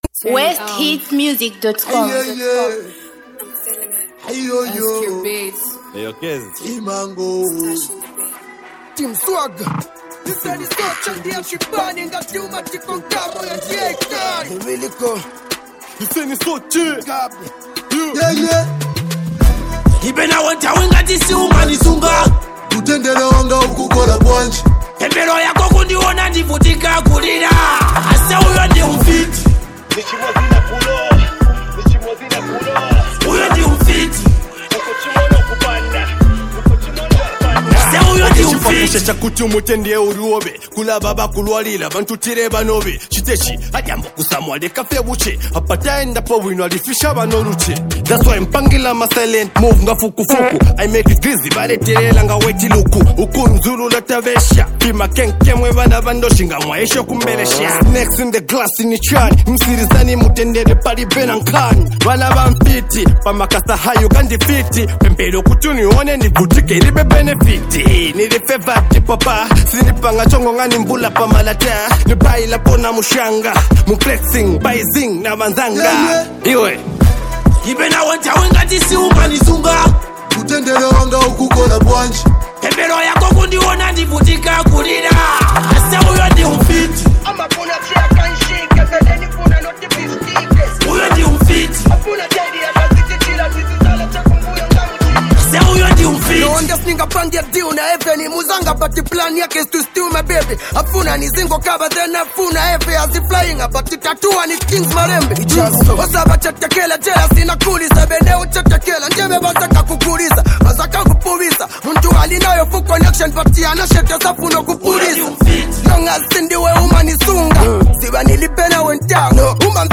Hip Hop TruckZambia Music